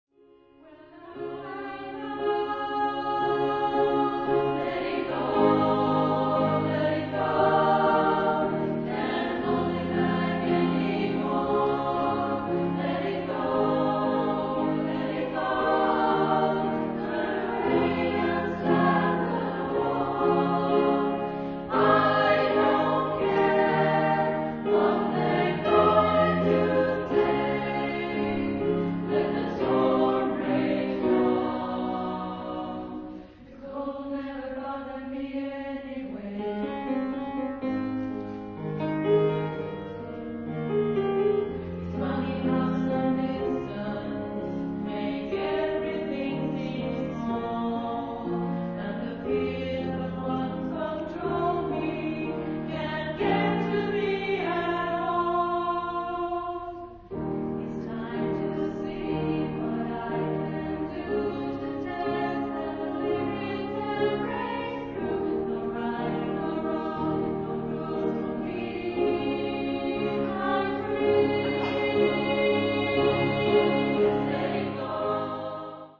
SSA + komp.
Register: S1:Ab3-Eb5, S2:Ab3-Bb4, A:F3-Ab4
Besättning: SSA
Tema/genre/epok: 10-tal , Filmmusik